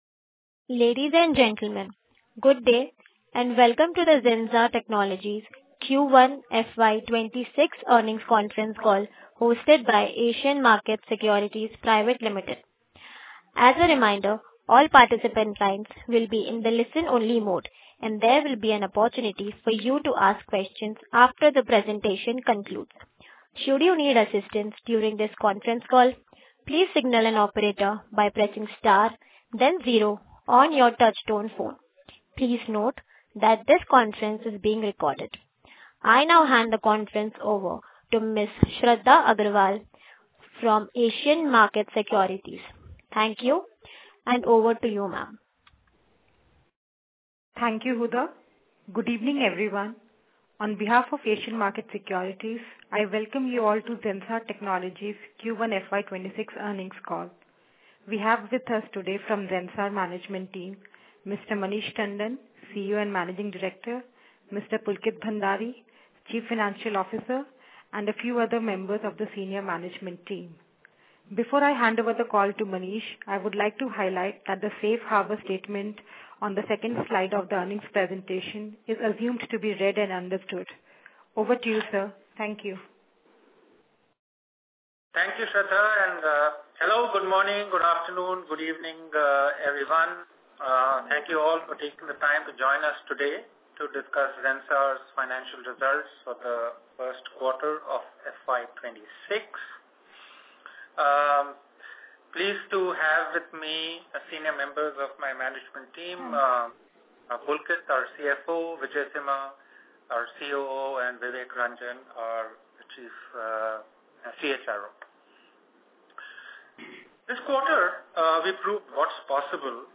Concalls
Q1FY26-Earning-Call-Recording.mp3